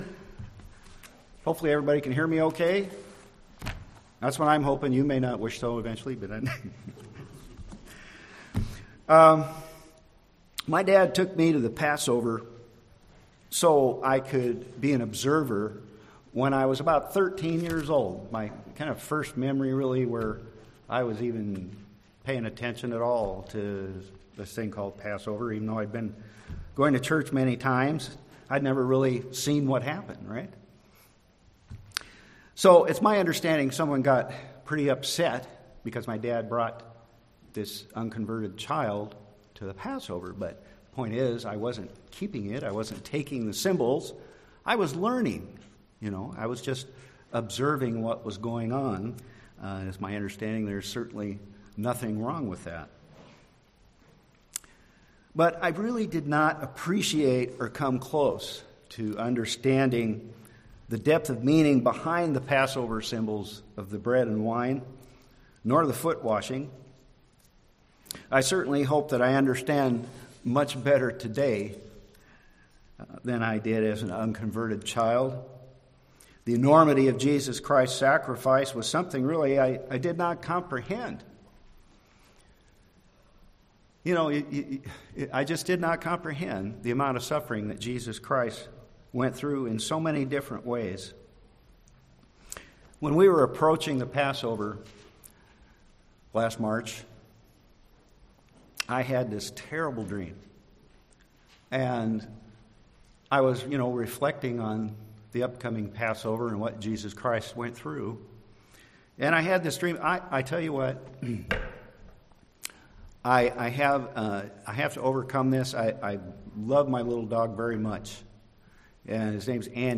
Listen to this sermon to learn why you shouldn't wait until the last minute to make sure you do not keep the Passover in an unworthy manner.